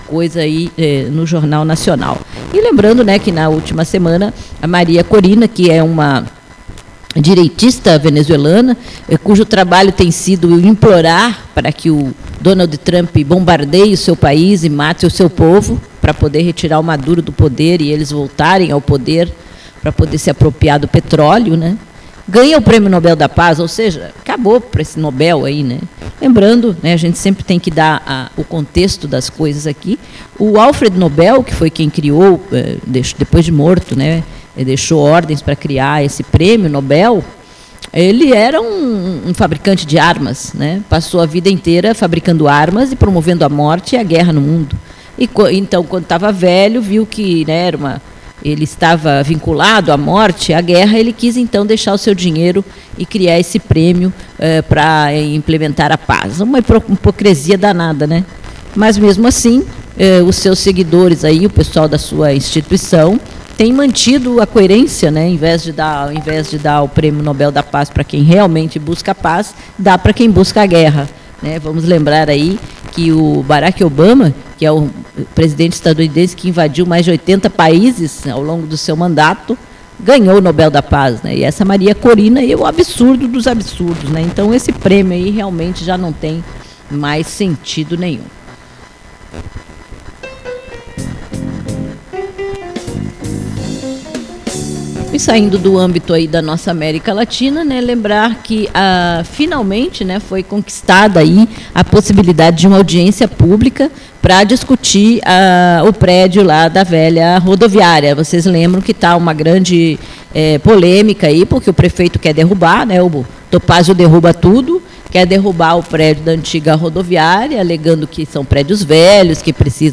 E tem a ver com o fato de que Florianópolis não tem saneamento básico. Entrevista ao Programa campo de Peixe.